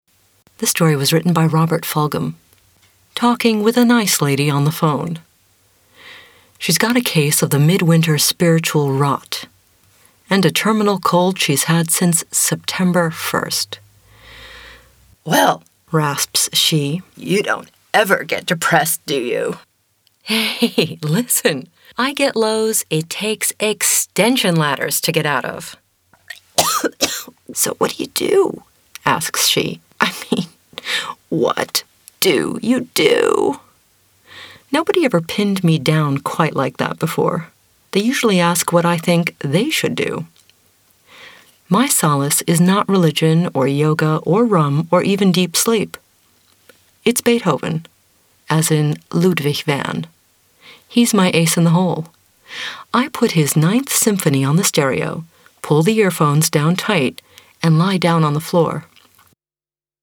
mid-atlantic
Sprechprobe: Industrie (Muttersprache):